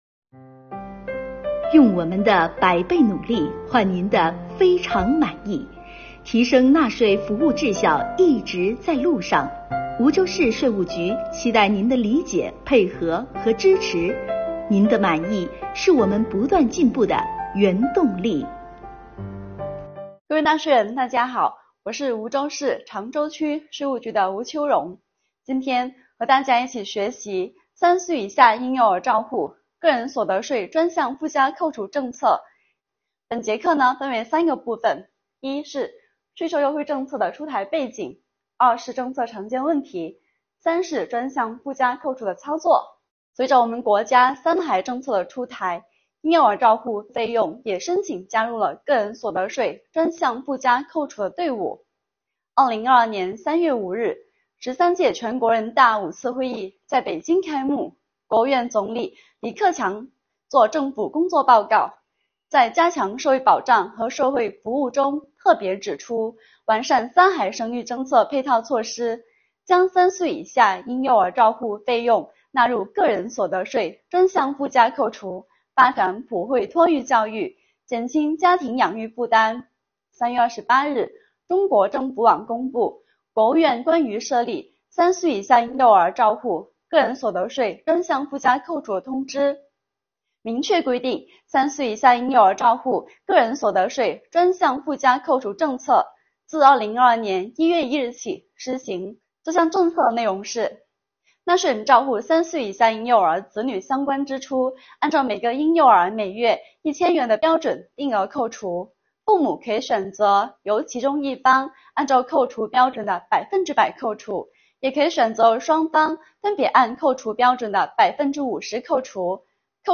政策讲解丨3岁以下婴幼儿照护个人所得税专项附加扣除